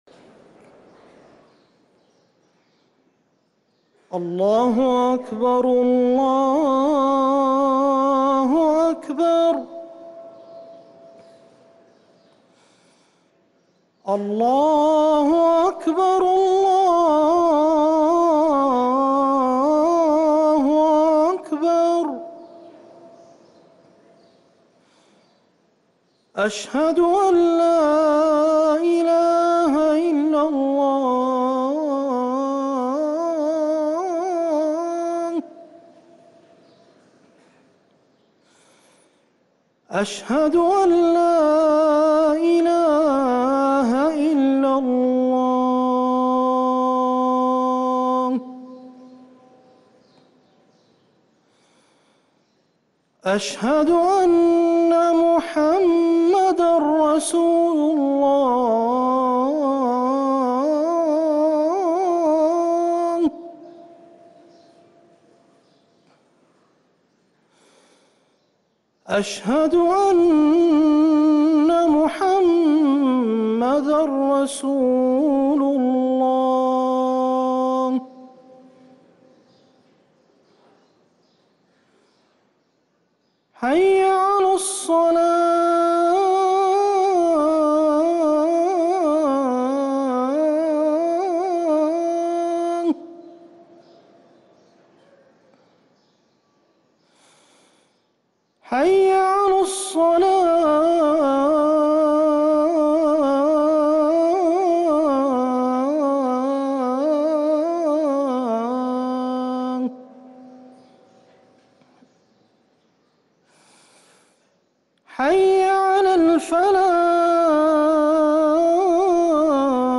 اذان الفجر للمؤذن عبدالمجيد السريحي الجمعة 14 محرم 1444هـ > ١٤٤٤ 🕌 > ركن الأذان 🕌 > المزيد - تلاوات الحرمين